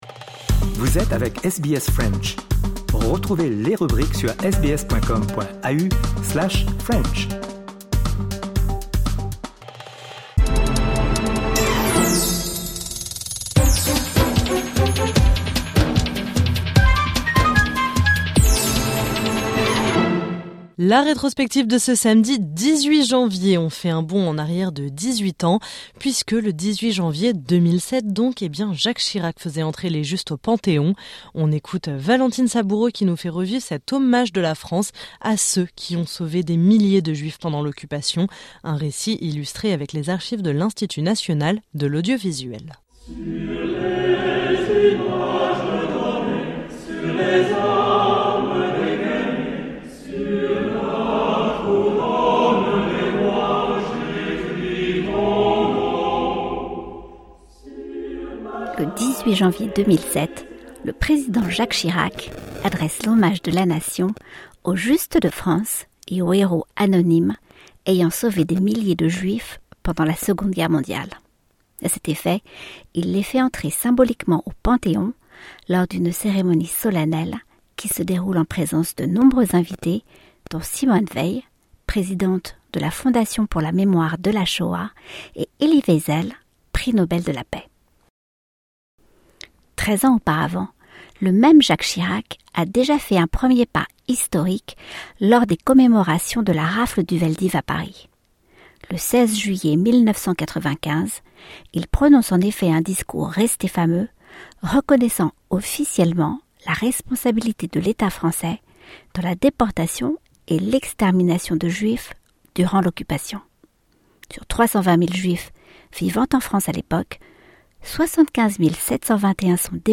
Un récit illustré avec les archives de l’Institut national de l’audiovisuel.